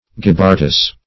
Search Result for " gibbartas" : The Collaborative International Dictionary of English v.0.48: Gibbartas \Gib*bar"tas\, n. [Cf. Ar. jebb[=a]r giant; or L. gibber humpbacked: cf. F. gibbar.]